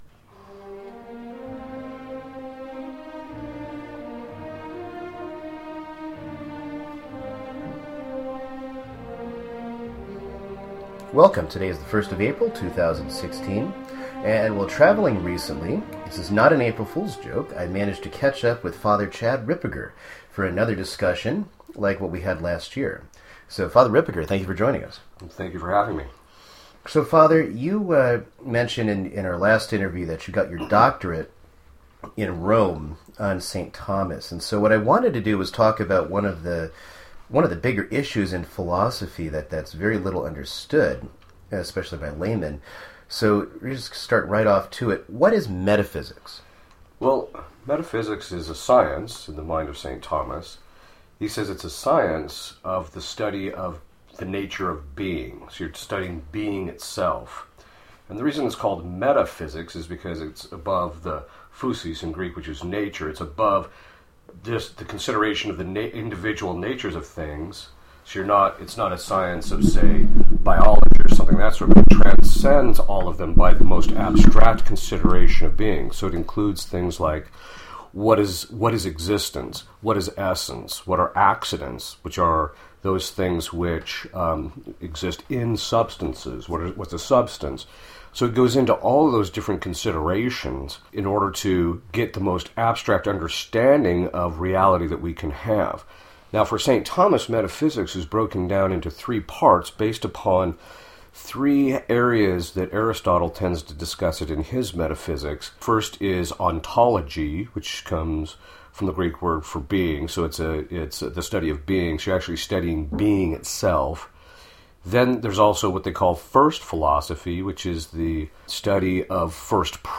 Interview 028